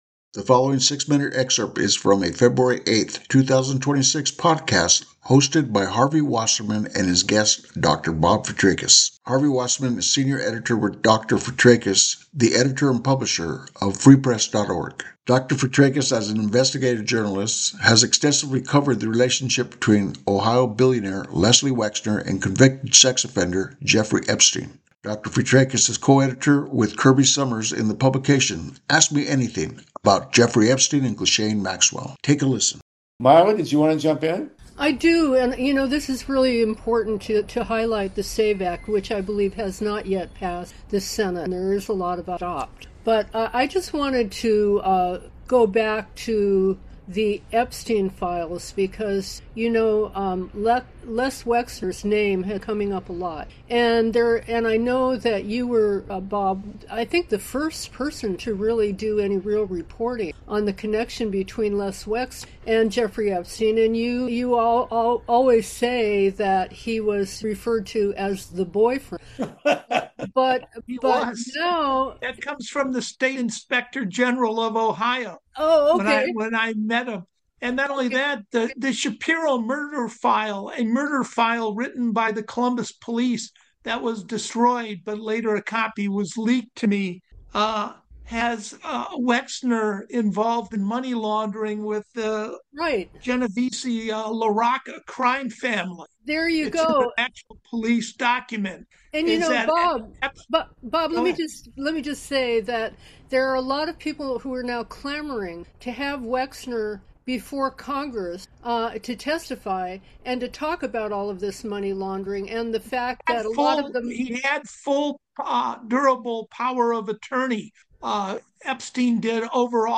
Excerpt From Solartopia/Grassroots Election Protection Zoom Feb. 9, 2026
Zoom Video capture